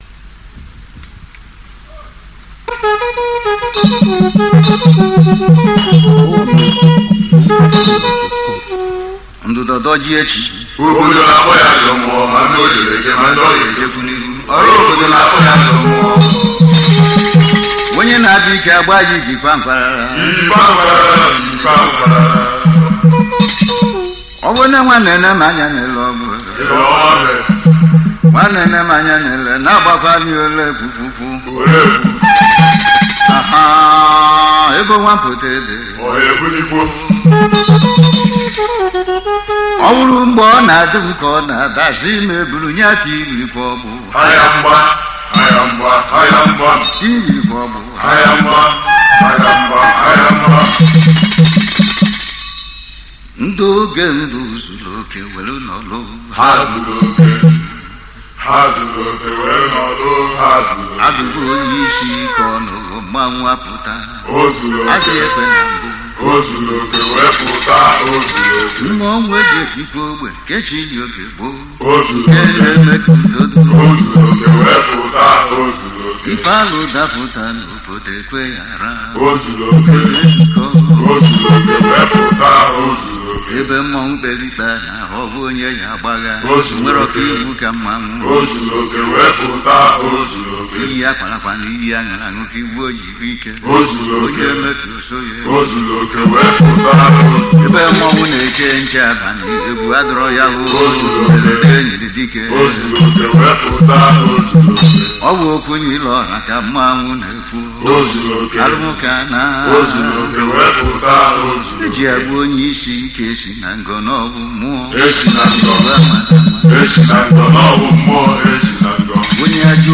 Home » Ogene